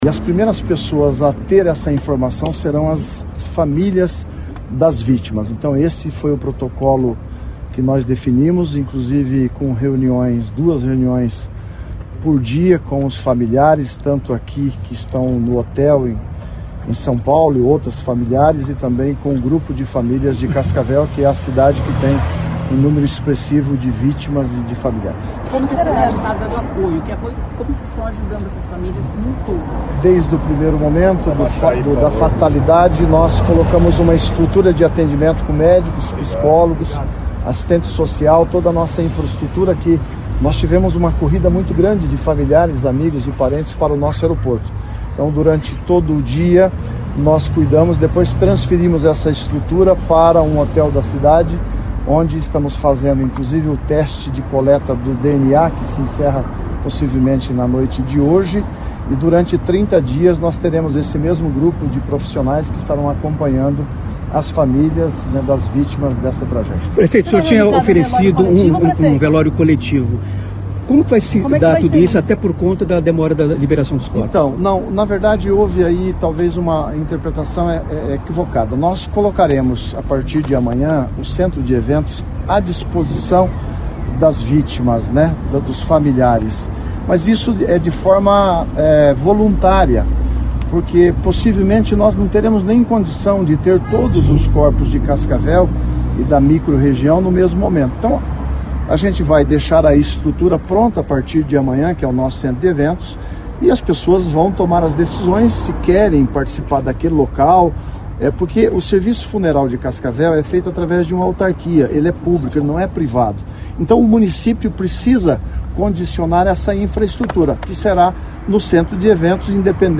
Player Ouça O PREFEITO PARANHOS EM COLETIVA REALIZADA EM SÃO PAULO NESTE DOMINGO